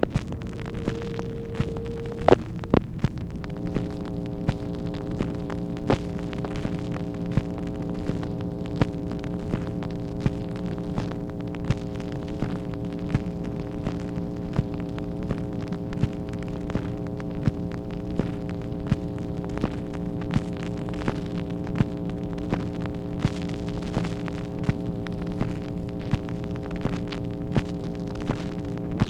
MACHINE NOISE, August 13, 1964
Secret White House Tapes | Lyndon B. Johnson Presidency